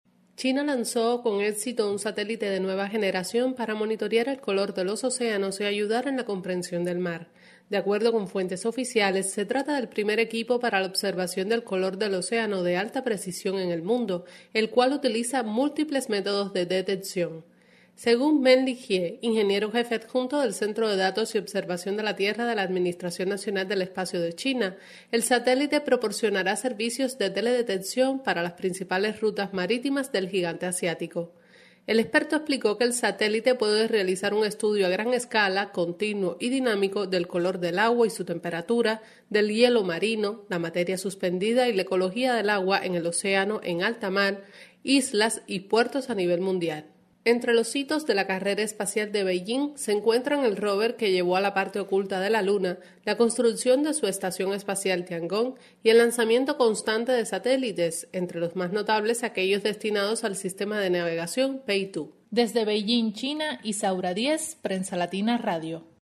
desde Beijing